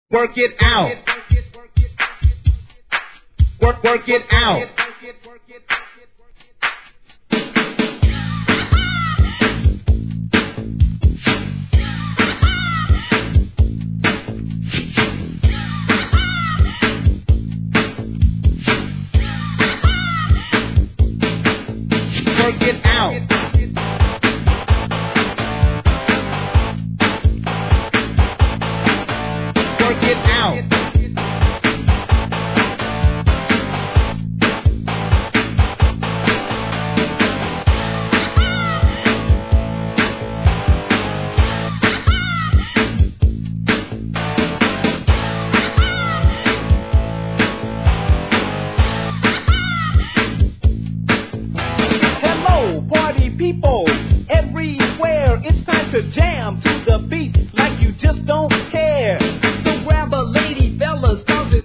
HIP HOP/R&B
1989年、ROCKなBEATでのRAP!!